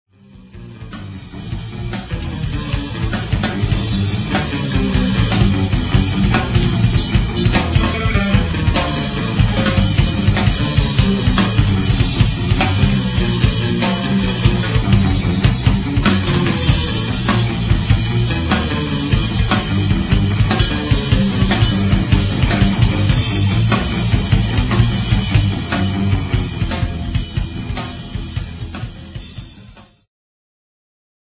Segment Jazz-Rock
Progressive
Ambient
Instulmental